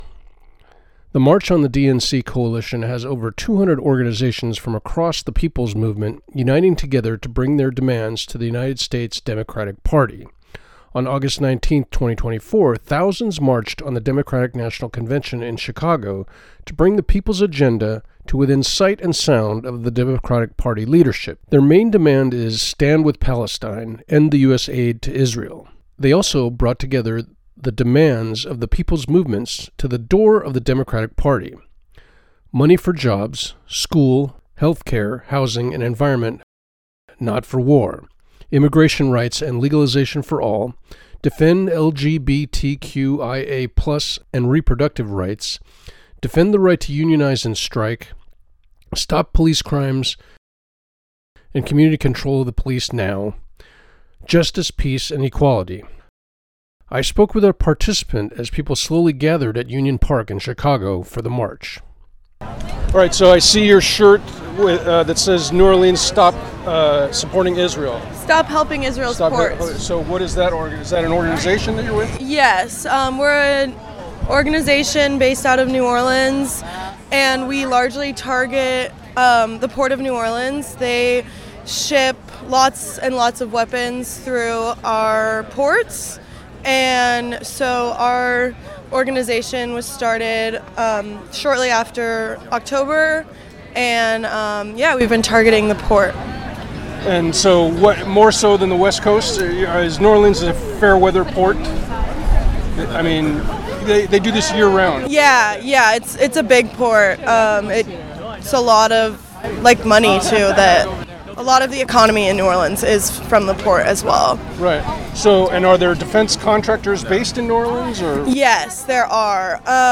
Interview from 1st day of 2024 Democratic National Convention Protest with member of a youth group trying to stop weapons from being shipped to Israel from the United States from port of New Orleans.